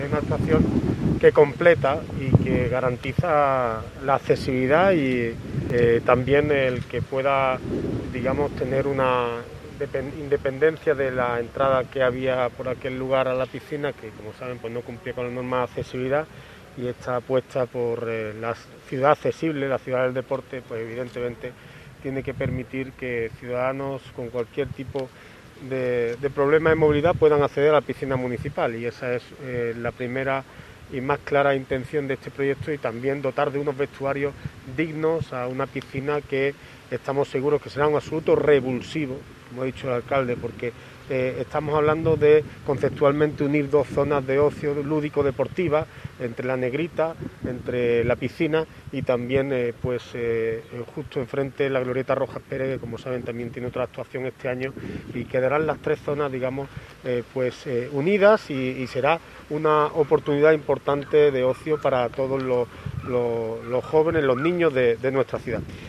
El alcalde de Antequera, Manolo Barón, y el concejal delegado de Obras, José Ramón Carmona, han anunciado el tercer proyecto que se acometerá próximamente en nuestra ciudad con cargo al Plan para la Recuperación Económica y del Empleo en la Provincia de Málaga que impulsa la Diputación Provincial.
Cortes de voz